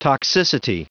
Prononciation du mot toxicity en anglais (fichier audio)
Prononciation du mot : toxicity